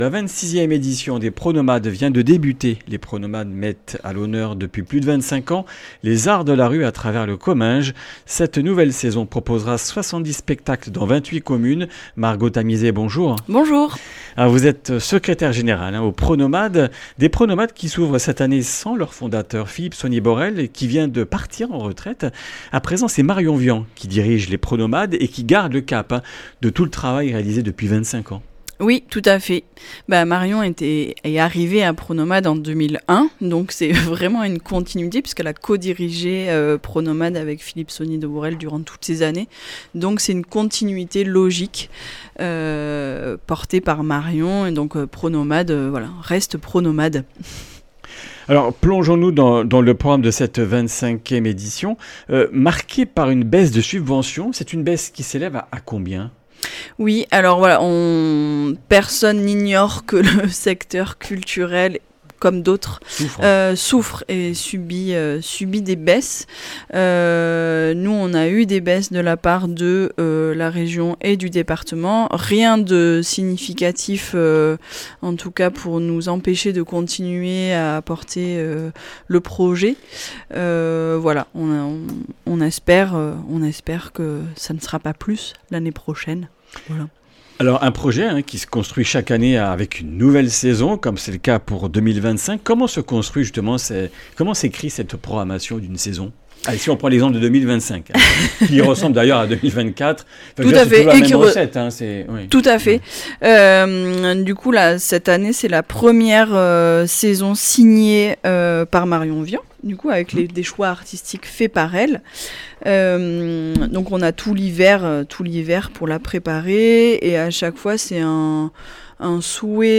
Comminges Interviews du 12 mai